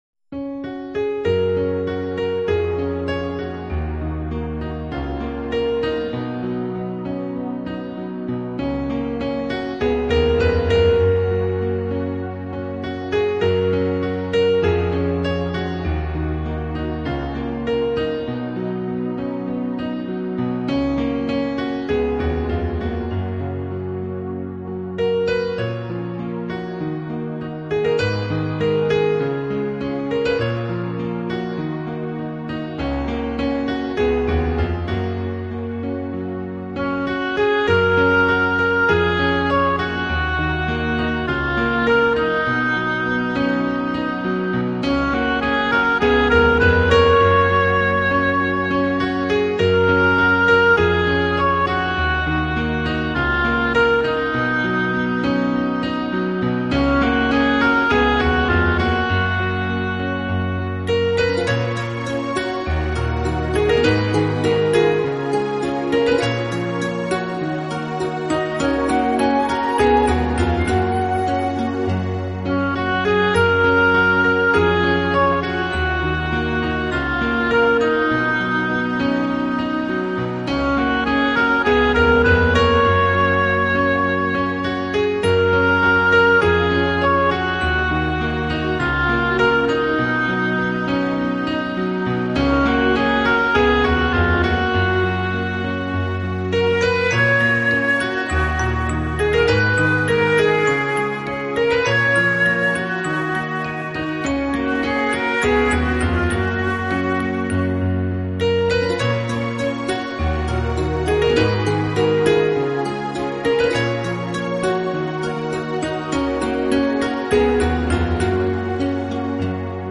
来自瑞士尘不染的音符空灵飘渺的音乐世界
清新的配器架构出零压力、零负担的乐曲，更细酌每一轨声道的解析度，使音场效
果更具空灵感，你将体验到浑圆完满的声线，就连声波最细微的毛边都完整接收。